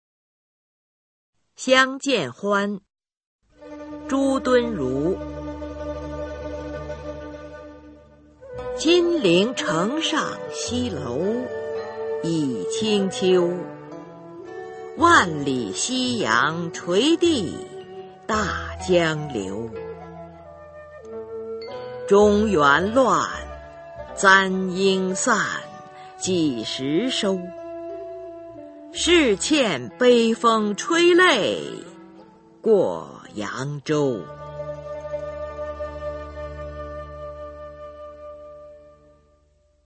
[宋代诗词诵读]朱敦儒-相见欢 宋词朗诵